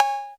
808COW.wav